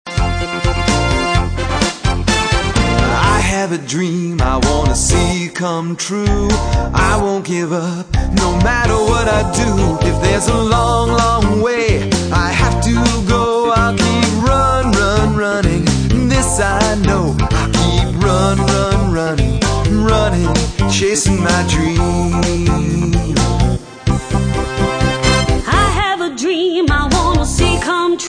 An Upbeat Motivational Song